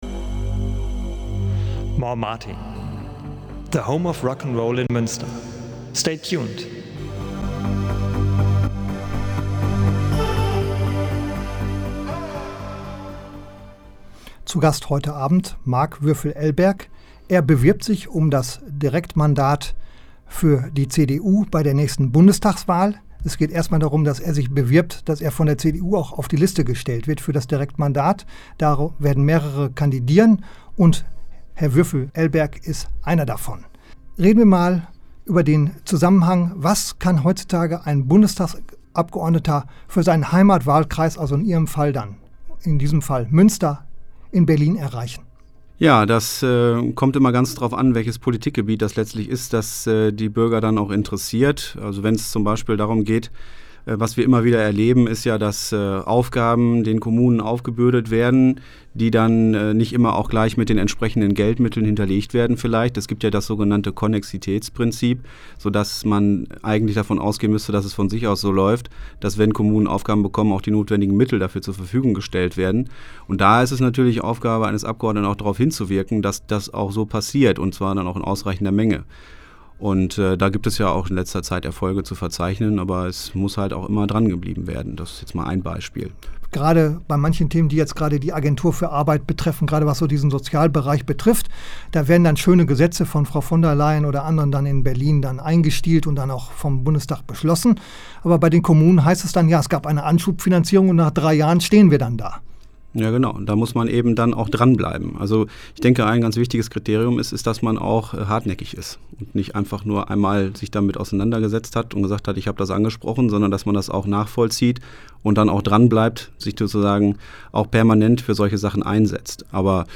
Die CD zu meinem Radio-Interview ist nun fertig und hier können die Interview-Teile jetzt heruntergeladen werden ("Mehr klicken")